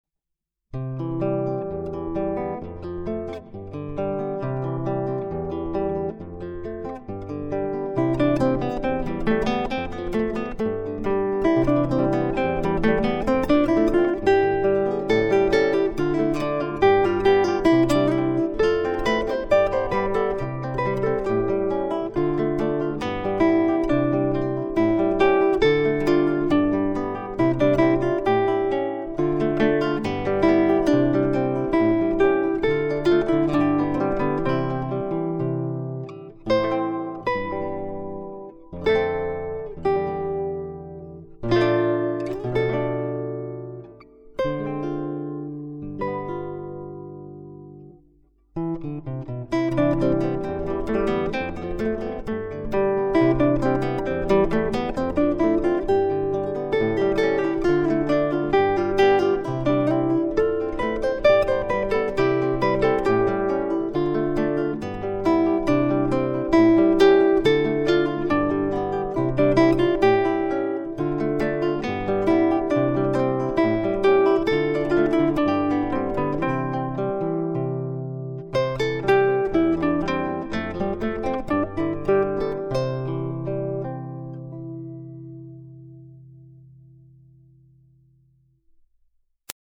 (pour 2 guitares)
"Un petit air de printemps", enregistré par l'auteur